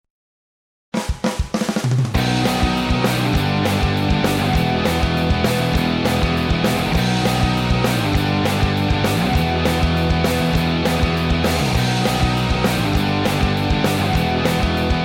Adrenaline, Metal, Techno